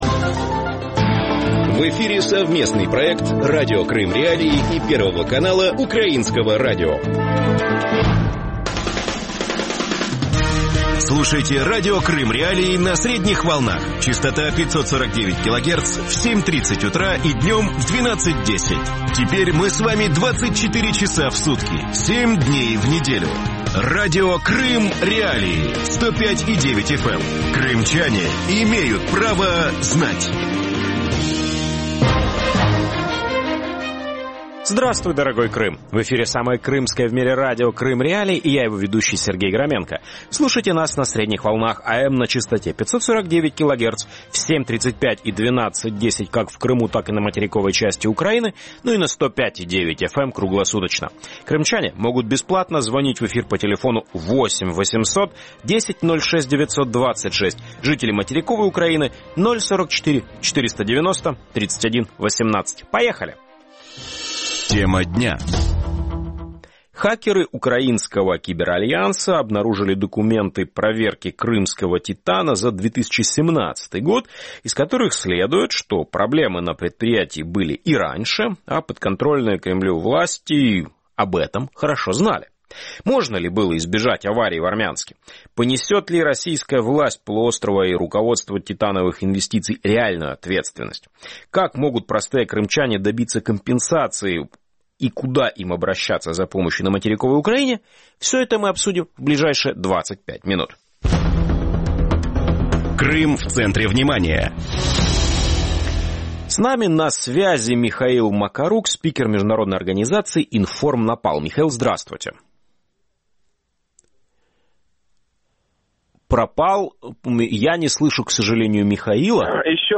Радио Крым.Реалии вещает 24 часа в сутки на частоте 105.9 FM на северный Крым.